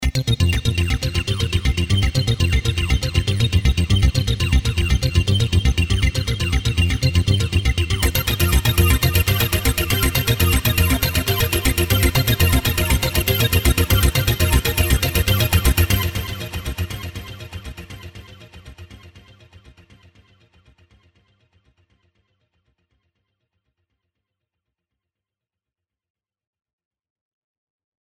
今回作成した音色のデモサウンドです。
最初に押さえていたノートの上で和音を押さえた状態のフレーズとなっています。
コードモードを使用するとデモサウンドのような演奏が行なえる。
その他のポイントとしては、仕上げのエフェクトとしてディレイ、リバーブの他、シェイパーを使用しています。
ポリフォニック・シンセサイザー